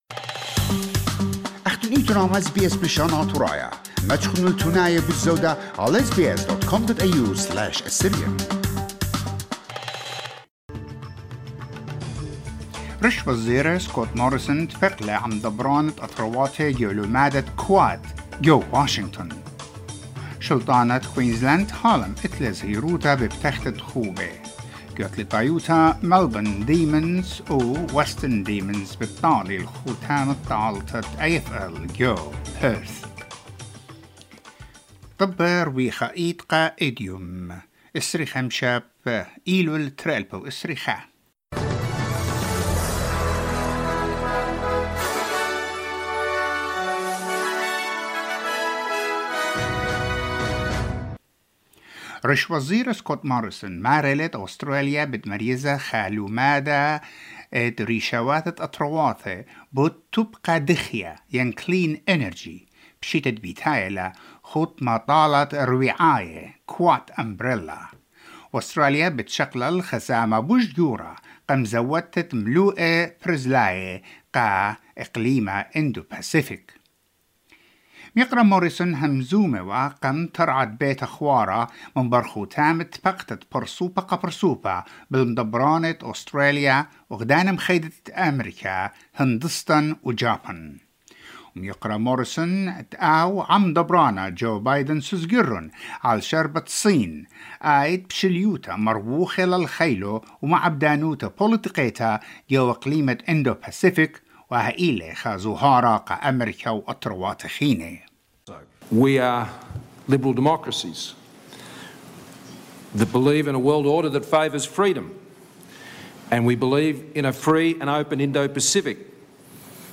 NEWS BULLETIN FOR SATURDAY 25 SEPTEMBER 2021